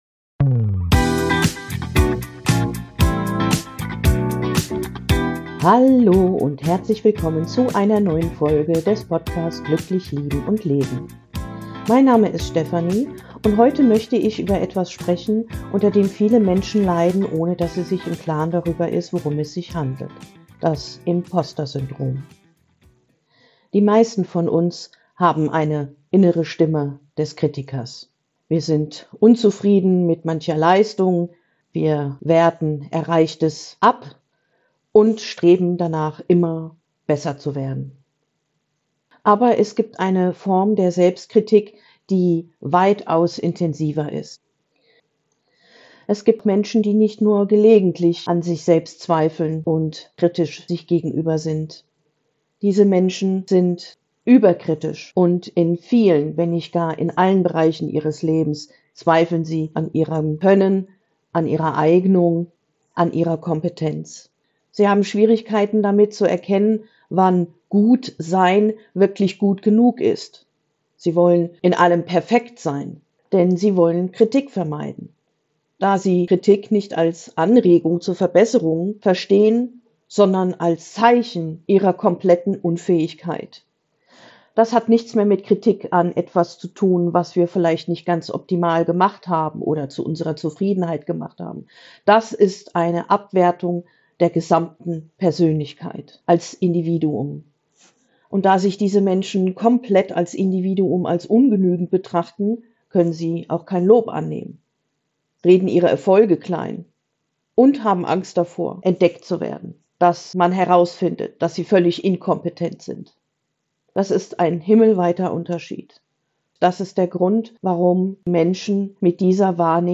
Am Ende begleitet dich eine kurze hypnotische Entspannung, die hilft, Anerkennung anzunehmen und deinen Selbstwert zu stabilisieren.